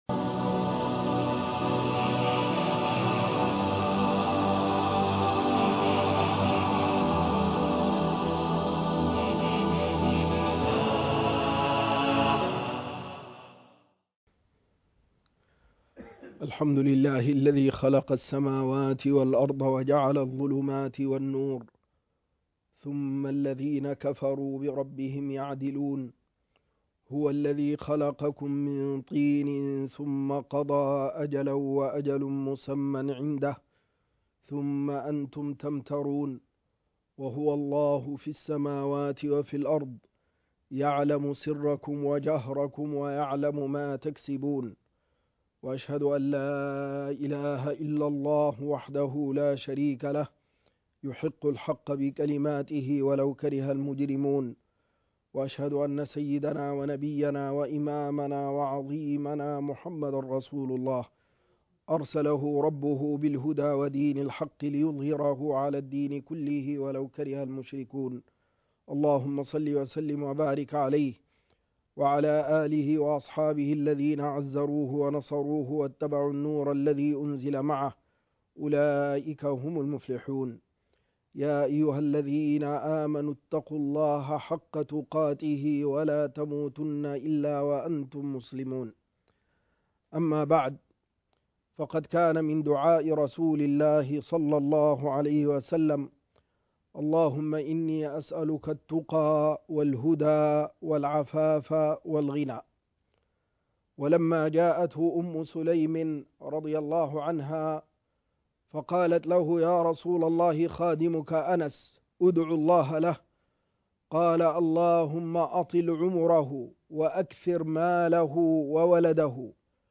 الغنىٰ المحمود....والاستعاذة من الفقر..خطبة الجمعة